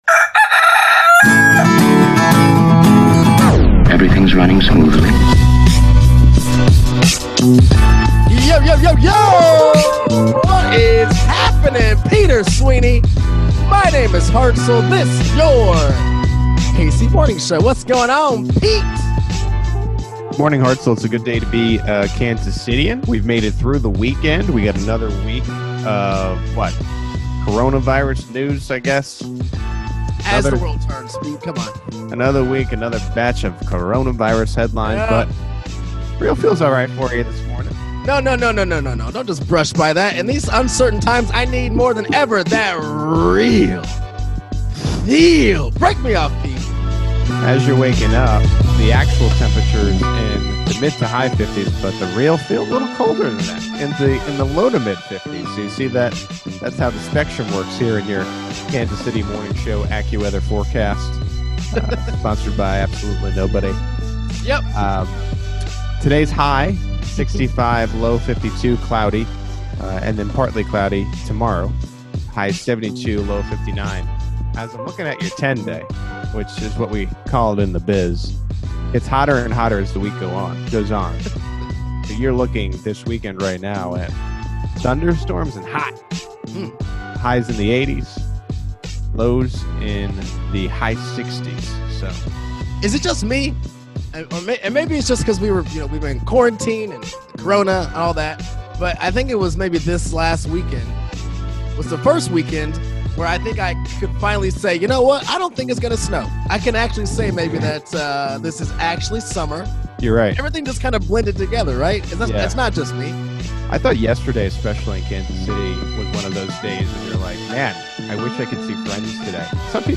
KCMO Mayor Quinton Lucas joins the program as the city begins to slowly re-open (33:22).